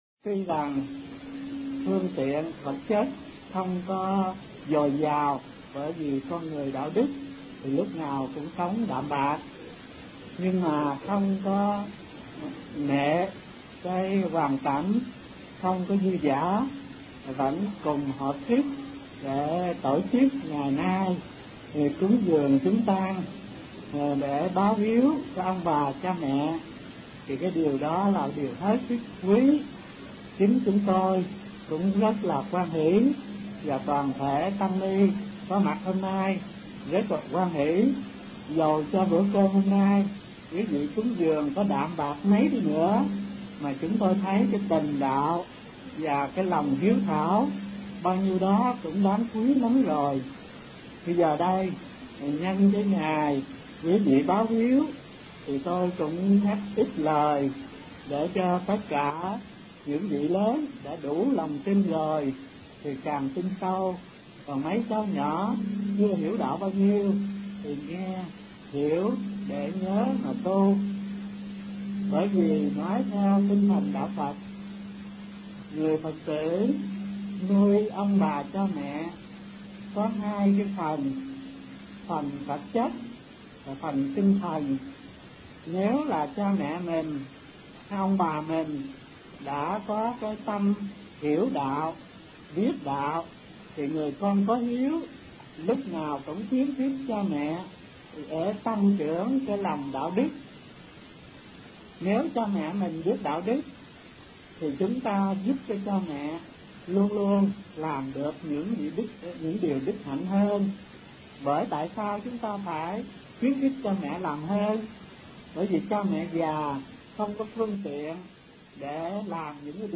Kinh Giảng Cách Báo Hiếu Lâu Dài - Thích Thanh Từ